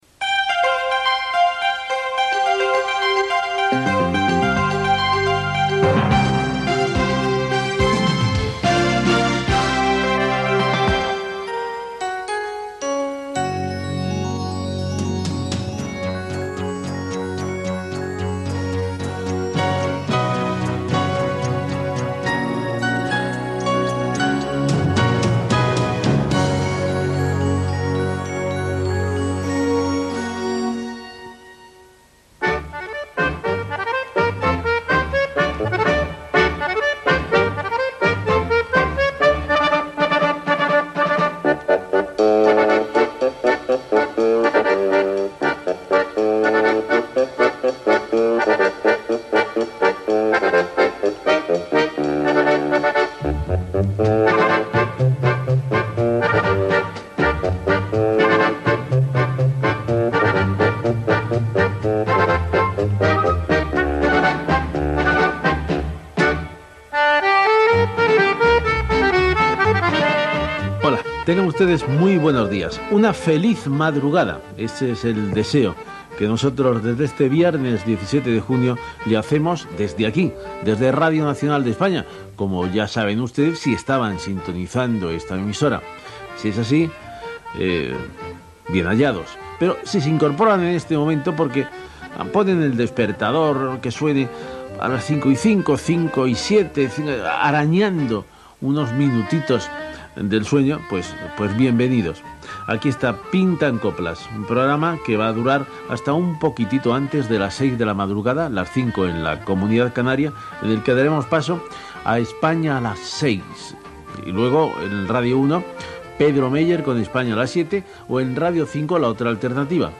Indicatiu musical de l'emissora, sintonia del programa, presentació inicial amb esment als programes següents de les emissores de RNE.
Musical